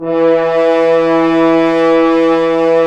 Index of /90_sSampleCDs/Roland LCDP06 Brass Sections/BRS_F.Horns 1/BRS_FHns Ambient
BRS F.HRNS05.wav